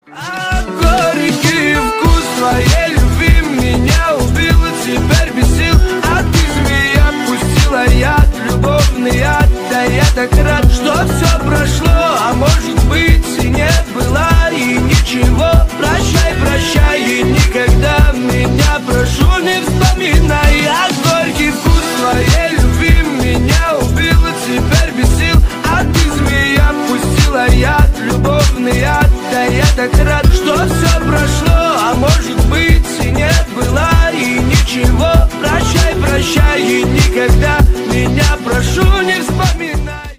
Ремикс # кавказские # клубные